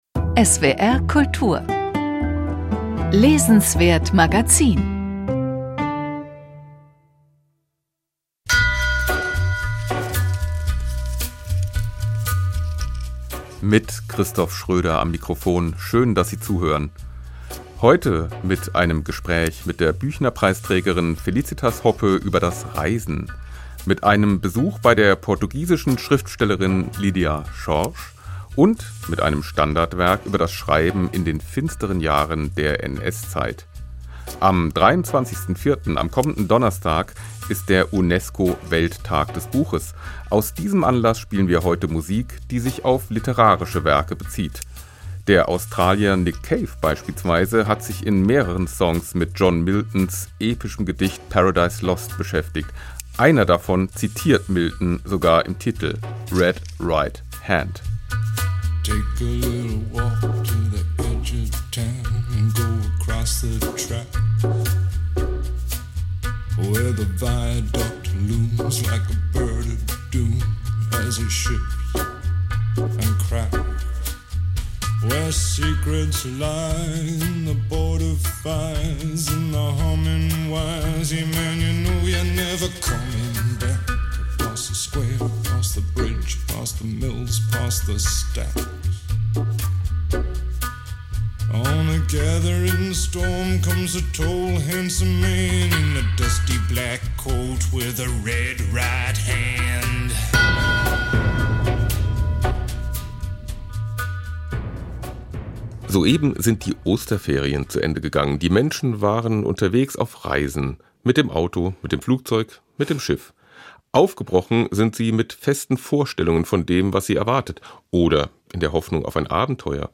Ein Gespräch mit Felicitas Hoppe über das Reisen, ein neues Standardwerk zur Literatur im Nationalsozialismus sowie Bücher von Lídia Jorge, Steffen Kopetzky und Peggy Mädler.